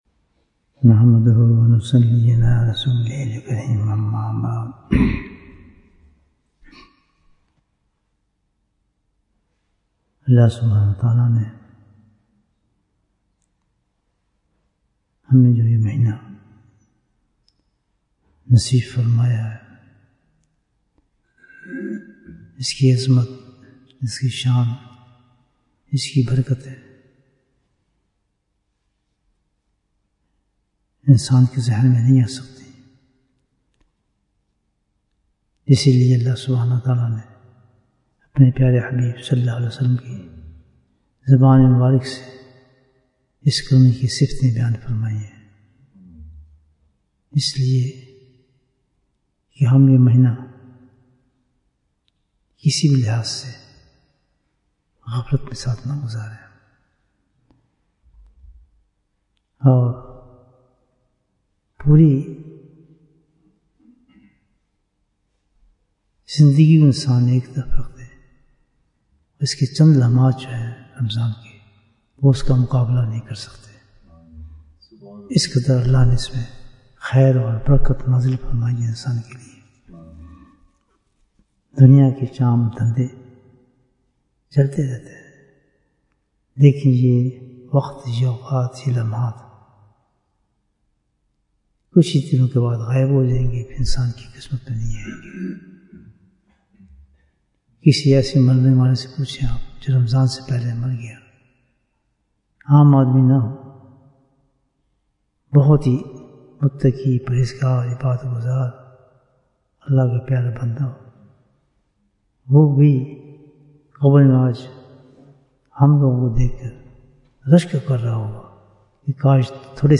Bayan, 29 minutes5th March, 2025